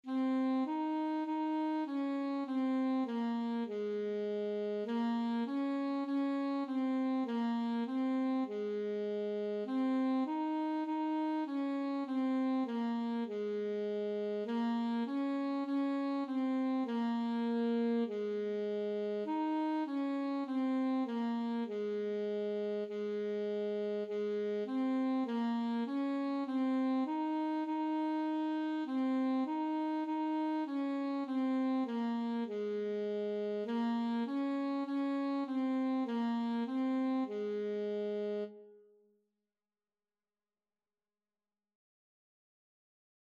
Free Sheet music for Alto Saxophone
4/4 (View more 4/4 Music)
Ab4-Eb5
Classical (View more Classical Saxophone Music)